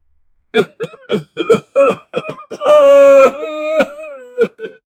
A man starts crying.
a-man-starts-crying-kt6usyqt.wav